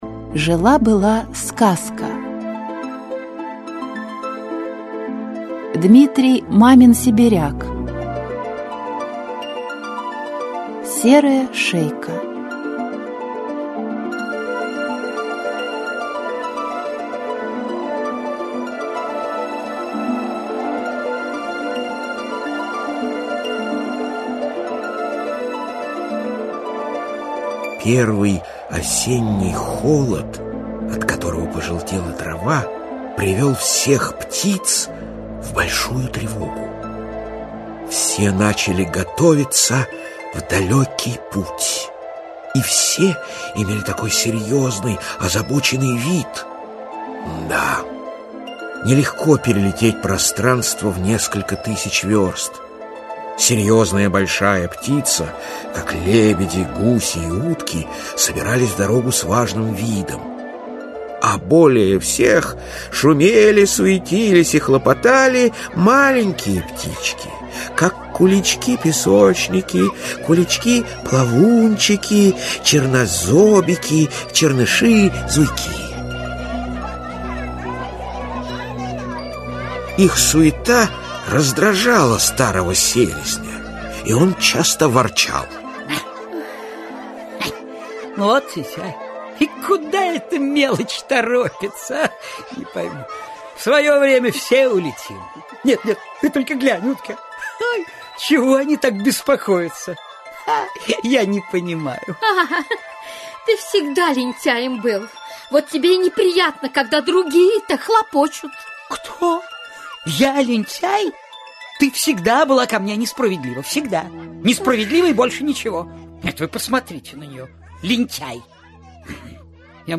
Аудиокнига Серая Шейка. Аудиоспектакль | Библиотека аудиокниг